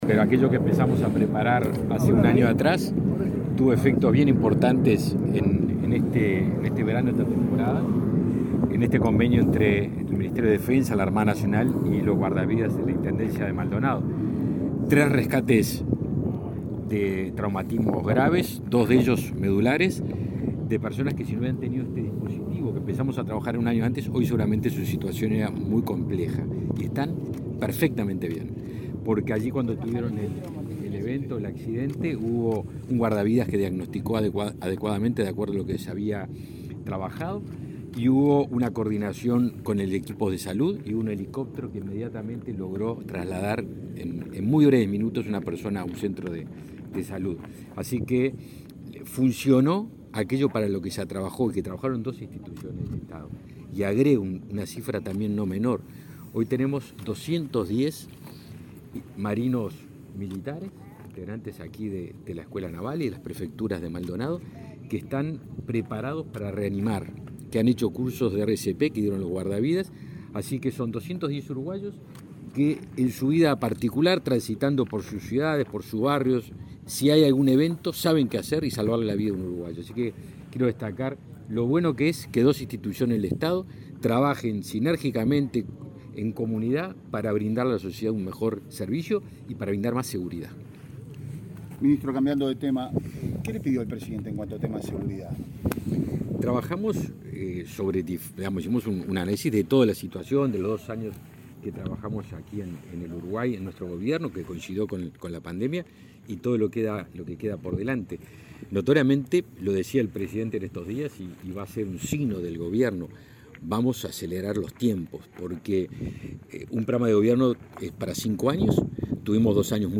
Declaraciones a la prensa del ministro de Defensa Nacional, Javier García
Declaraciones a la prensa del ministro de Defensa Nacional, Javier García 06/06/2022 Compartir Facebook X Copiar enlace WhatsApp LinkedIn El ministro de Defensa Nacional, Javier García, participó, este lunes 6 en Maldonado, en una jornada de evaluación de la aplicación del convenio sobre salvaguarda de la vida en la costa en la pasada temporada. Luego, dialogó con la prensa.